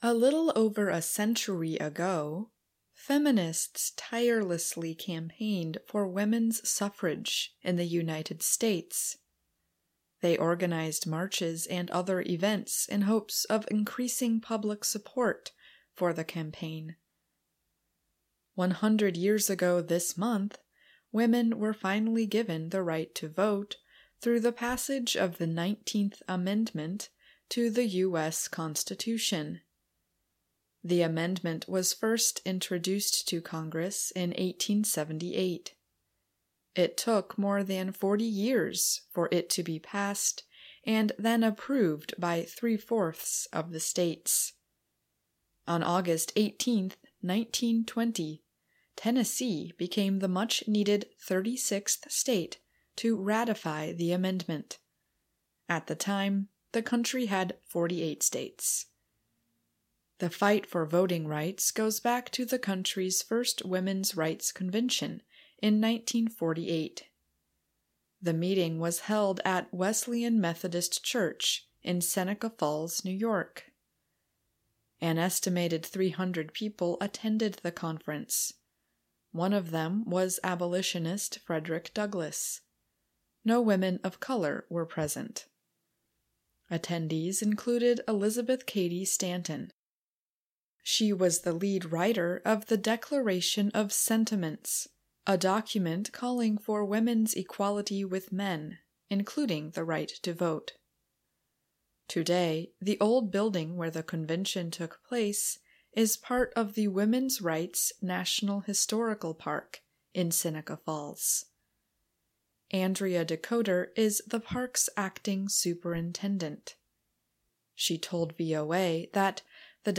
慢速英语:美国妇女获得选举权100周年